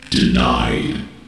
denied.ogg